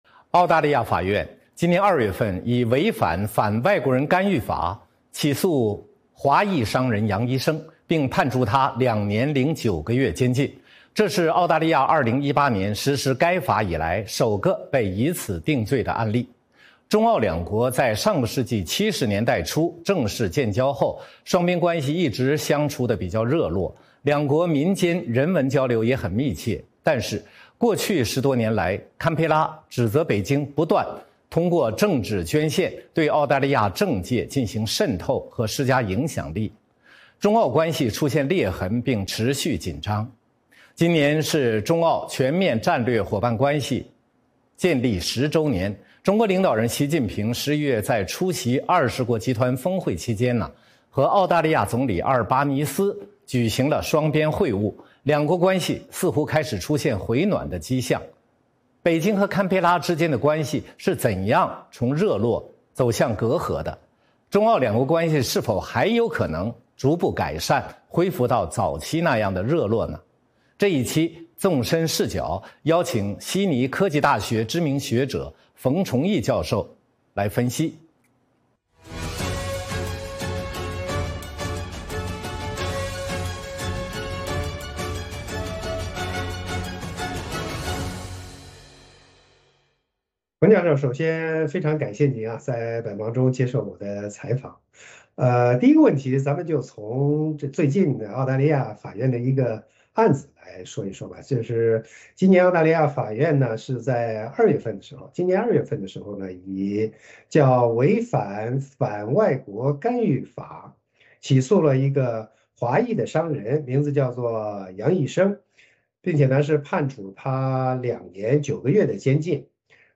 《纵深视角》节目进行一系列人物专访，受访者发表的评论不代表美国之音的立场 。